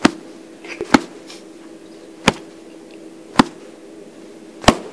打孔 " 在衣服上打孔
描述：现实生活记录了腹部的拳
Tag: 衣服 无花果HT 耳光 冲床 冲床